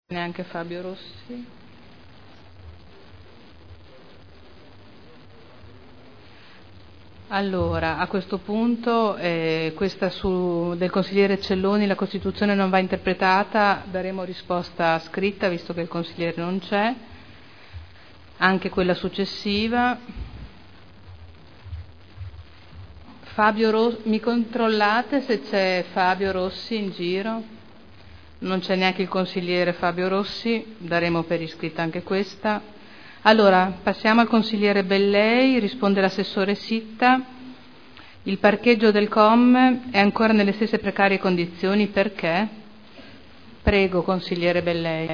Comunicazione della Preseidente Caterina Liotti su inteerogazioni 22, 19, 47